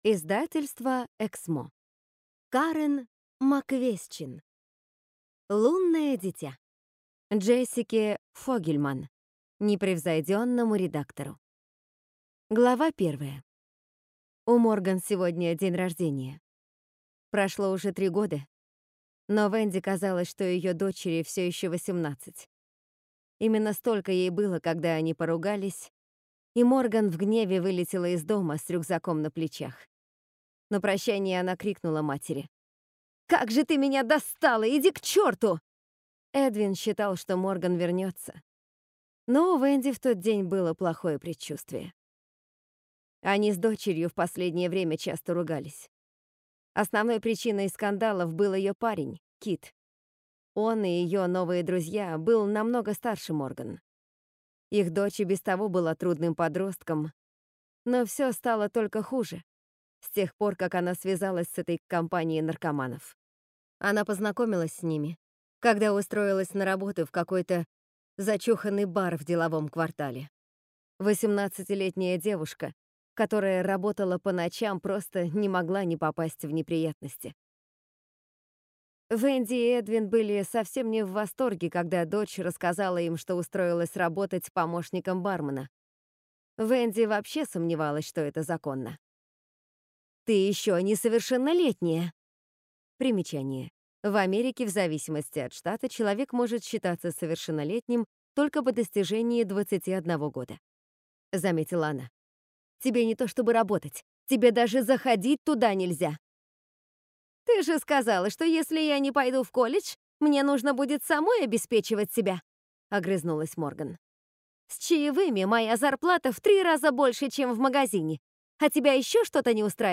Аудиокнига Лунное дитя | Библиотека аудиокниг